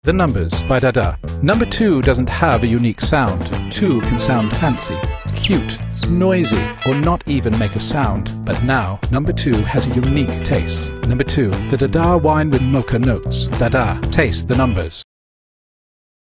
cool, precise, snobby